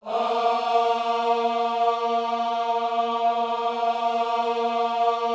OHS A#3D  -R.wav